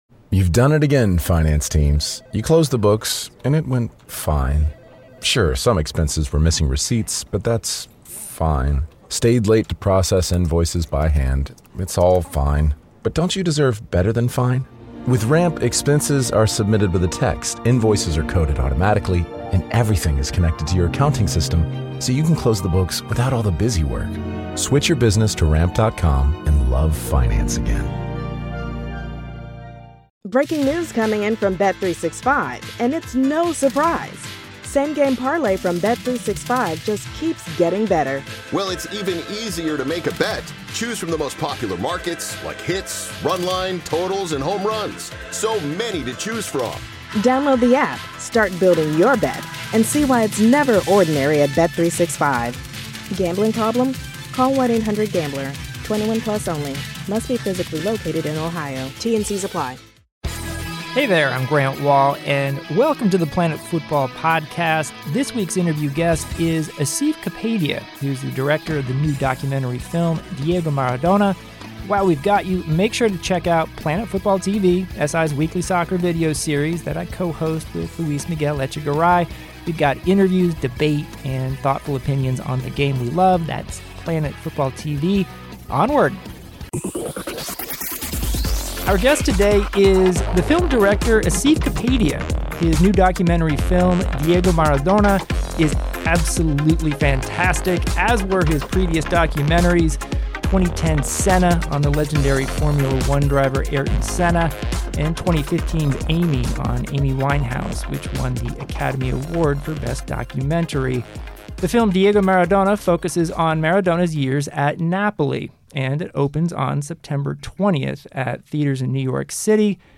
Grant speaks to Asif Kapadia, whose terrific new documentary film, Diego Maradona, opens in New York City theaters on September 20 and on October 1 on HBO. Kapadia, who also directed the films Senna (on the legendary Formula 1 driver Ayrton Senna) and the Academy Award-winning Amy (on Amy Winehouse), discusses why he chose Maradona as a subject and focused on his Napoli years, how he got connected to so much never-before-seen archival video, what it was like interviewing Maradona and the process of making the film.